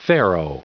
Prononciation du mot farrow en anglais (fichier audio)
Prononciation du mot : farrow